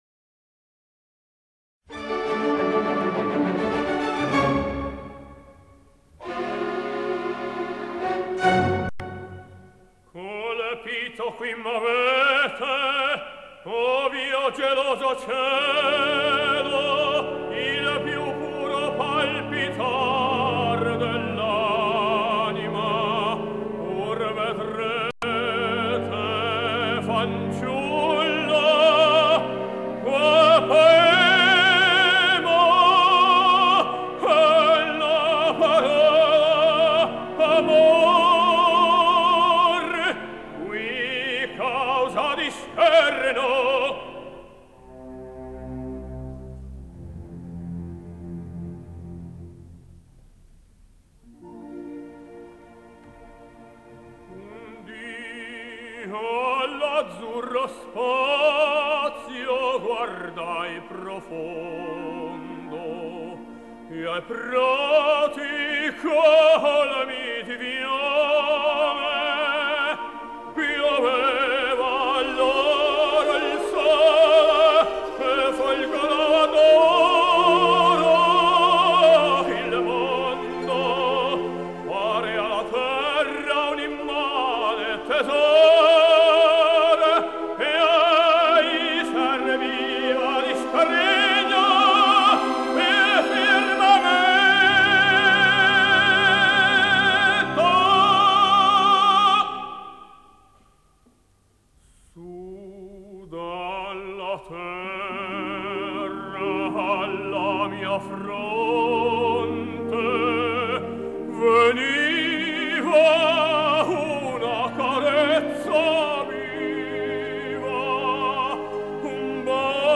由科雷里演唱。